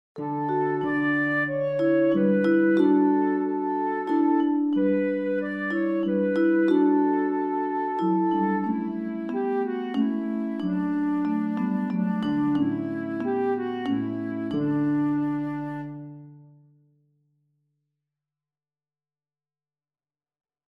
Meerstemmig / of met begeleiding
Langzaam, verheven